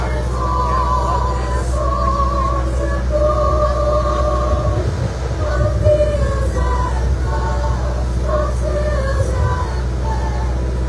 Downtown Emporia was a festive place for the Emporia Area Chamber of Commerce’s 47th annual Christmas Parade on Tuesday.
8582-choir-soiund.wav